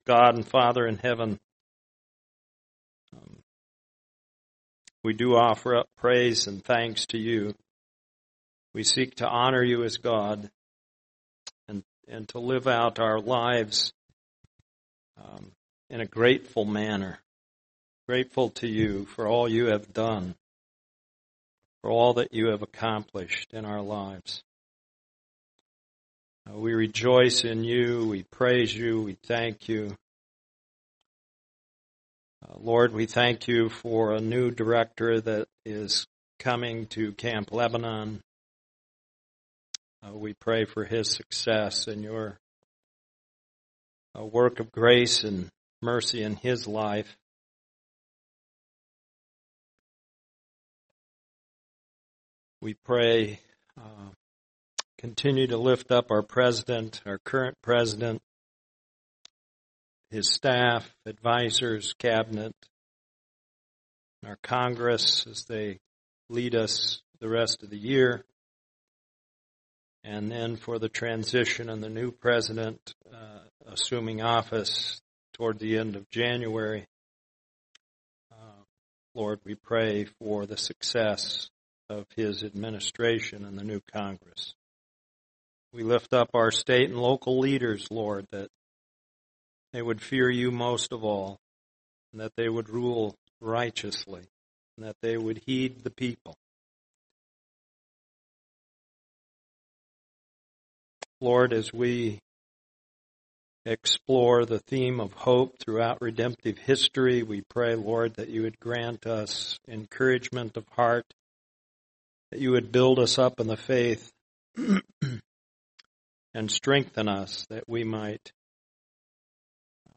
Sermons | Lake Phalen Community Church